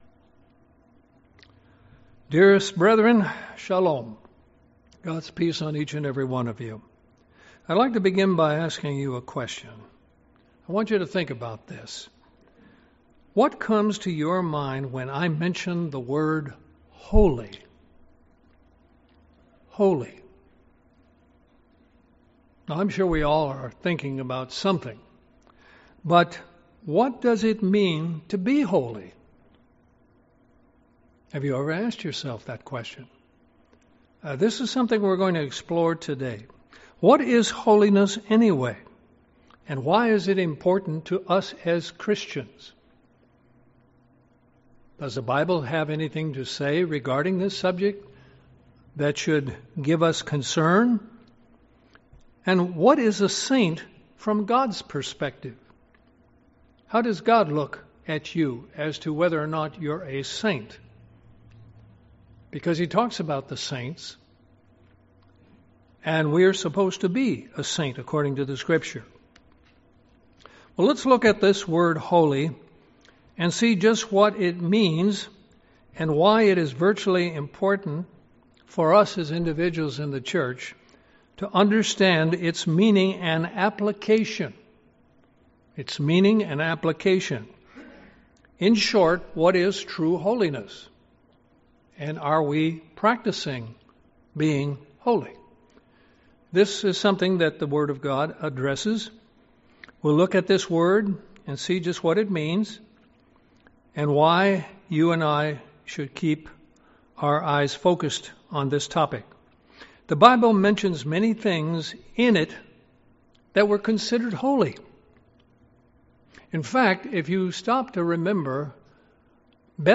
Sermons
Given in Columbus, GA Central Georgia